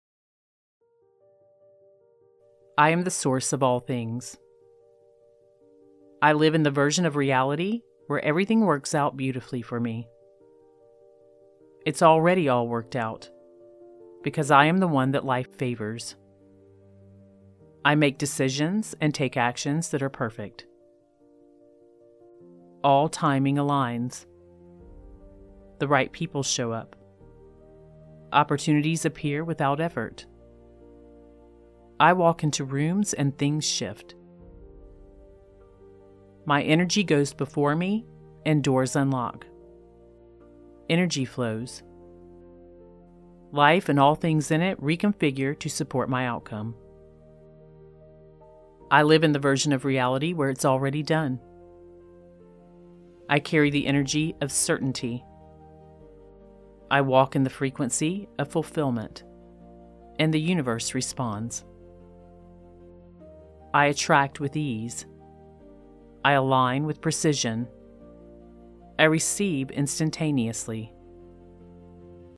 The session begins with a hypnotic activation that guides your awareness into a new level of self-perception.
A loop of high-impact affirmations plays continuously—designed to reinforce the belief that everything is already done.
🔊 Energetic Support with Subtle Theta Pulse
Set to a slow, commanding musical bed with theta-embedded tones, this track keeps your mind in a receptive state while elevating your emotional frequency.